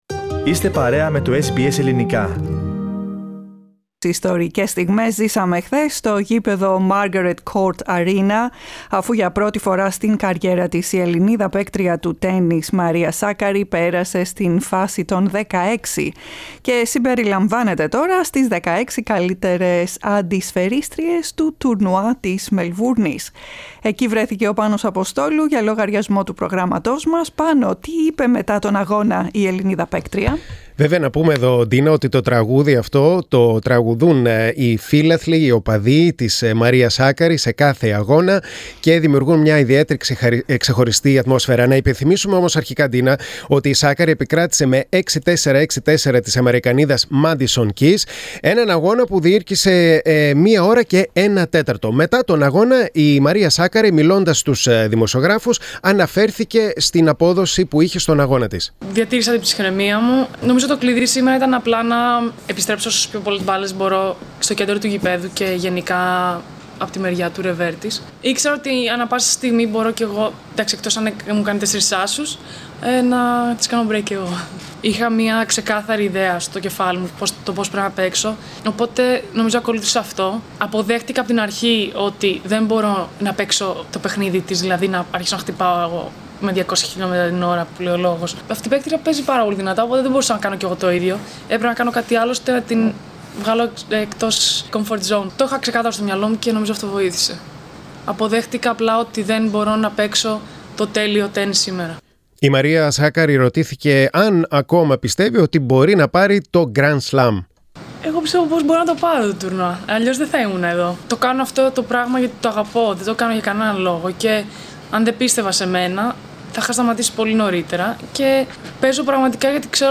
Τι είπε στη συνέντευξη Τύπου με τους Ελληνες δημοσιογράφους η Μαρία Σάκκαρη για το παιχνίδι της, για την Πέτρα Κβίτοβα, τους "εκλεκτούς" της φιλάθλους και για το Grand Slam.